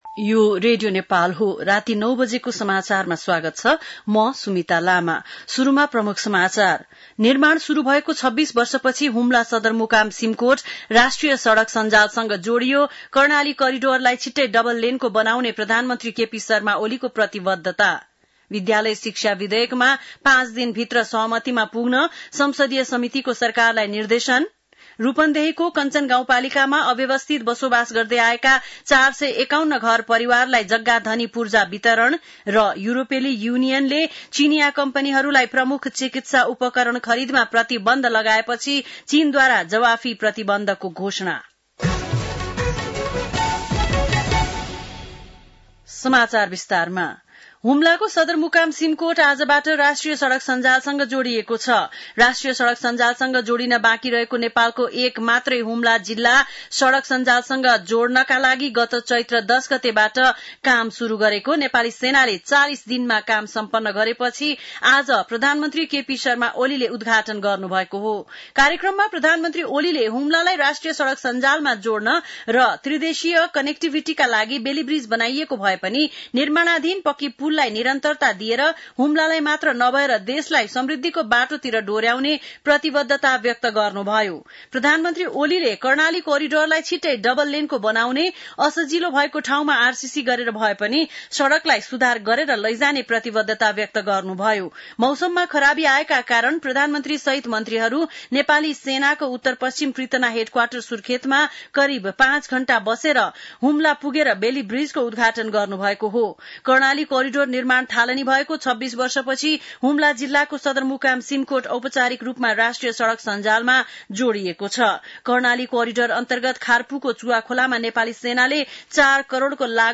बेलुकी ९ बजेको नेपाली समाचार : २२ असार , २०८२
9-pm-nepali-news-1-1.mp3